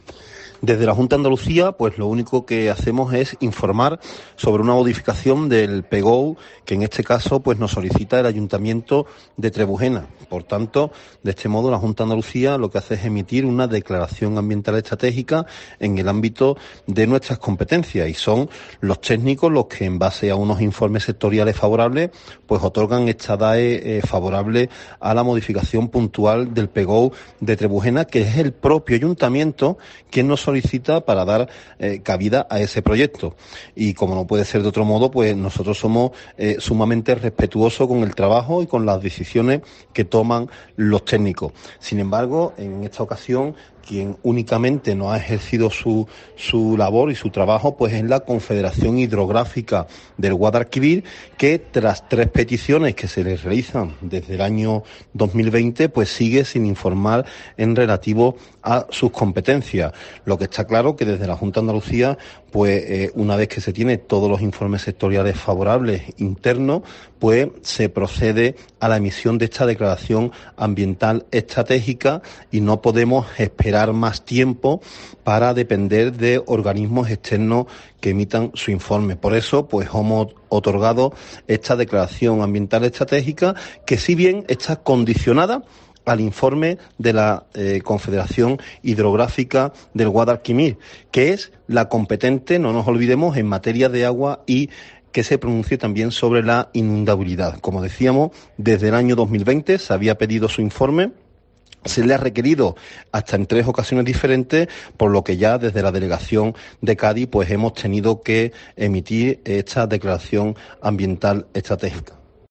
Escucha aquí al delegado territorial de Sostenibilidad, Medioambiente y Economía Azul, Oscar Curtido.